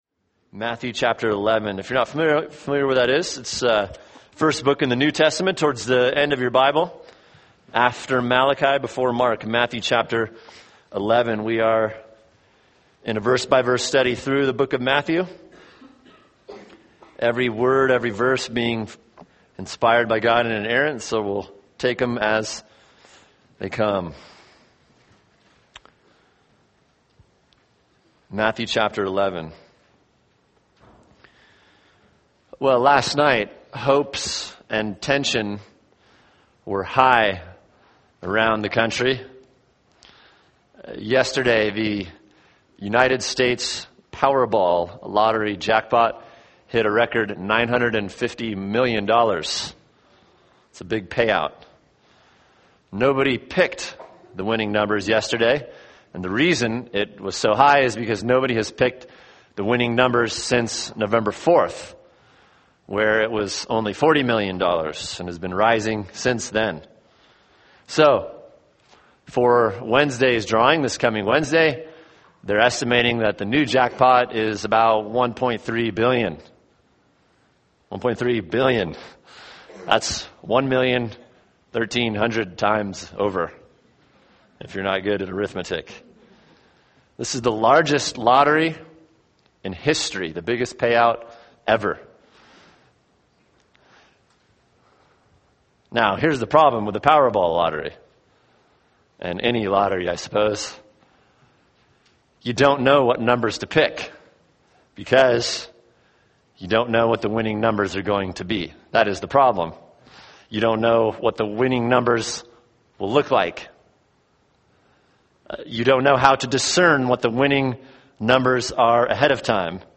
[sermon] Matthew 11:1-6 – Is Jesus the One?